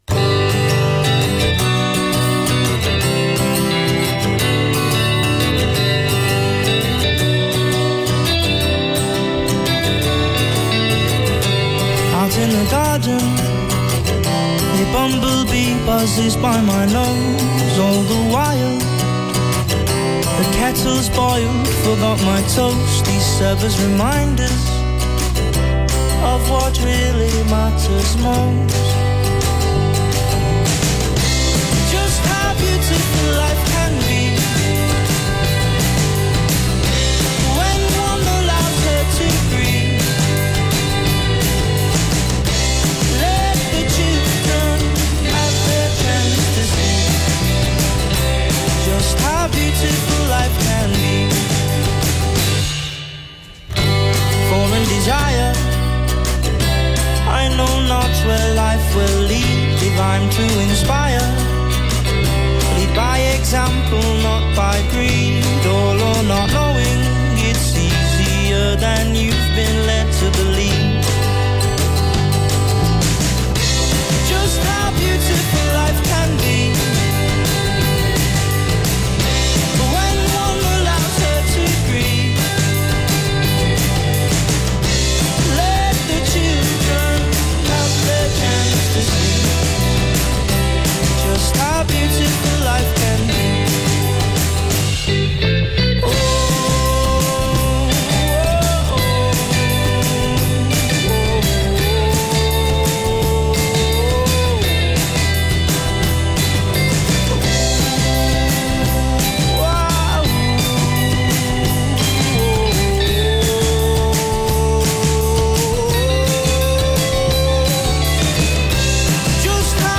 recorded only a few days ago
Marr-esque jangle guitar
bassist
drums
the jangly guitar outfit have amassed a huge following